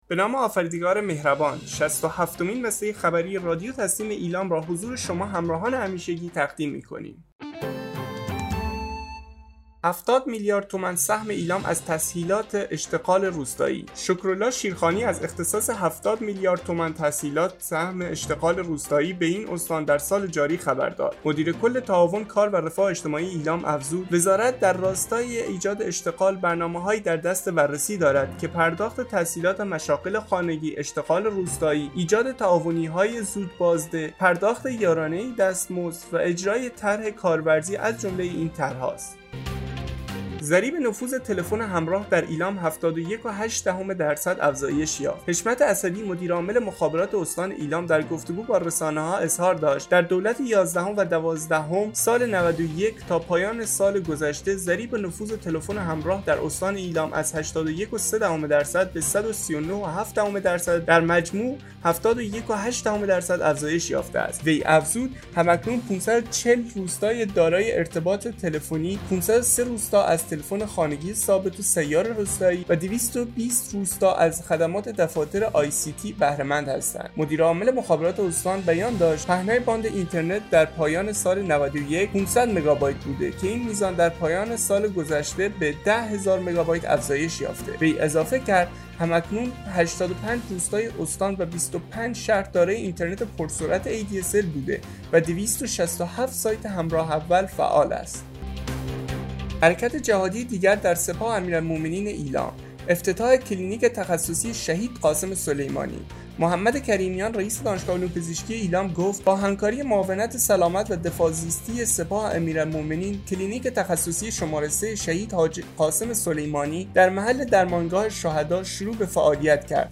به گزارش خبرگزاری تسنیم از ایلام, شصت و هفتمین بسته خبری رادیو تسنیم استان ایلام با خبرهایی چون 70 میلیارد تومان سهم ایلام از تسهیلات اشتغال روستایی، ضریب نفوذ تلفن همراه در ایلام 71.8 درصد افزایش یافت و حرکت جهادی دیگر در سپاه امیرالمومنین(ع) ایلام با افتتاح کلینیک تخصصی شهیدقاسم سلیمانی منتشر شد.